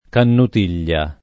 canutiglia [ kanut & l’l’a ]